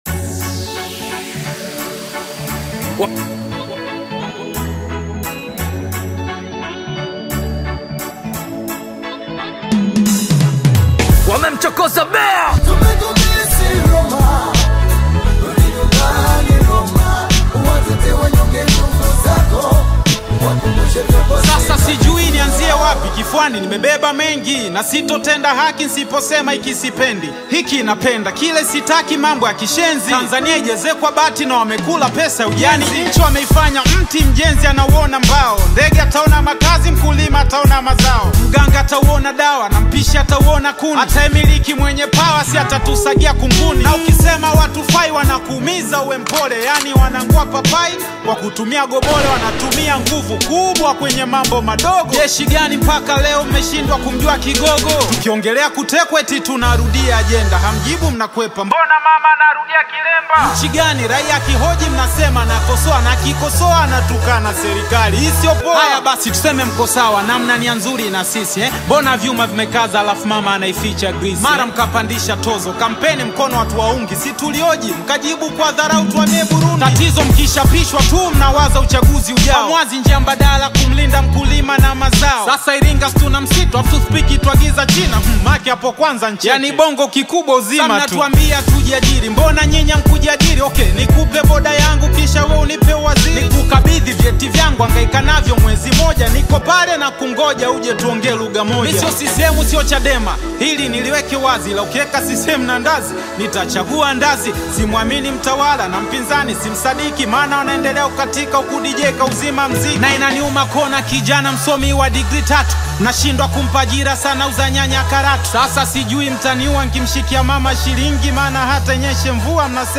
Hip-hop / Rap track